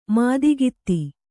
♪ mādigitti